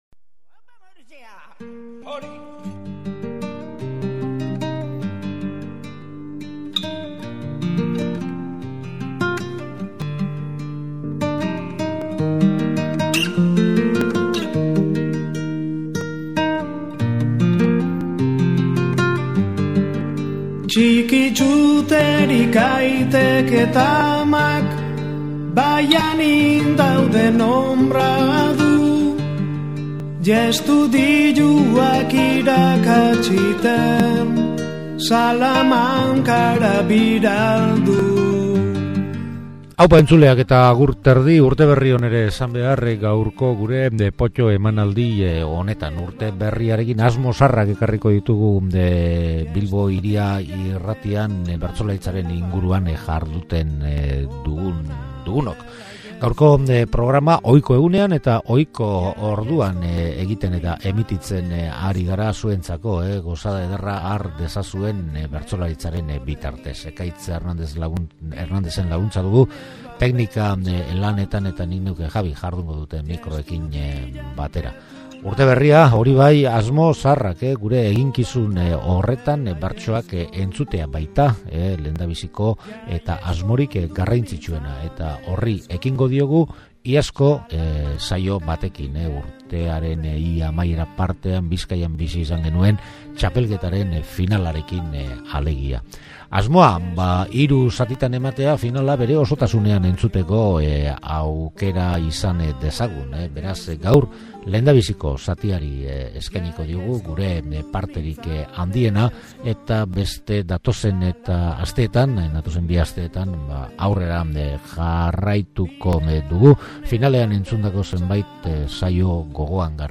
Gaurko POTTO emanaldian Abenduaren 16an jokatutako Bizkaiko finalari erreparatu diogu. Zortzi bertsolarien agurrak, eta ondoren bikoteka egindako zortziko handiko eta txikiko saioak entzuteko aukera ukanen dugu.